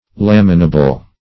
Laminable \Lam"i*na*ble\, a.